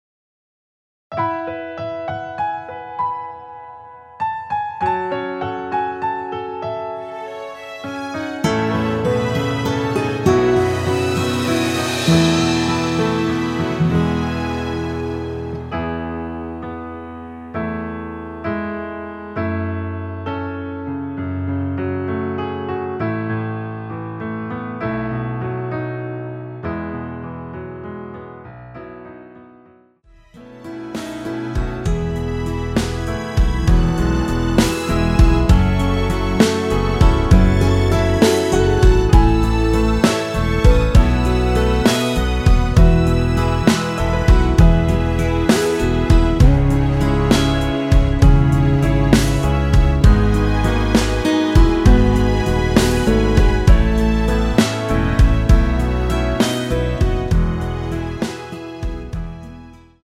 원키에서(-4)내린 MR입니다.
◈ 곡명 옆 (-1)은 반음 내림, (+1)은 반음 올림 입니다.
앞부분30초, 뒷부분30초씩 편집해서 올려 드리고 있습니다.